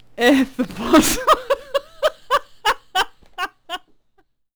khanat-sounds-sources/_stock/sound_library/voices/voice_acknowledgement/cudgel_ack7.wav at main
cudgel_ack7.wav